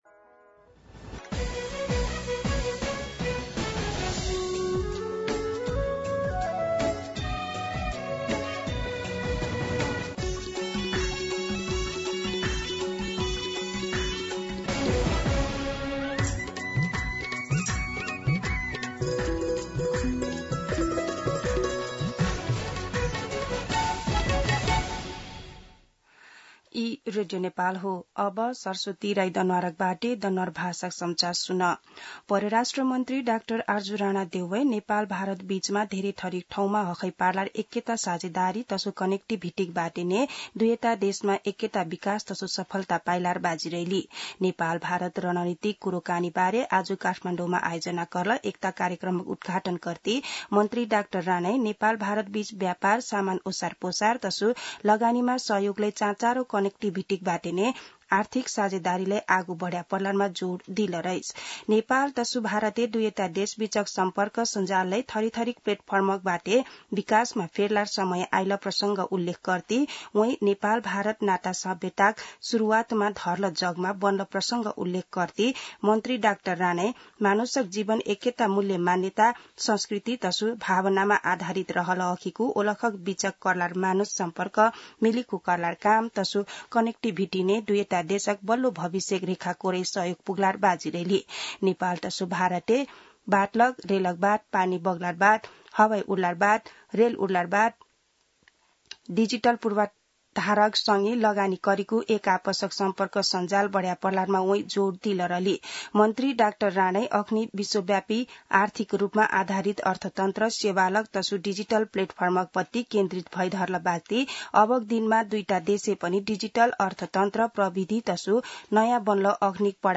दनुवार भाषामा समाचार : २९ जेठ , २०८२
Danuwar-News.mp3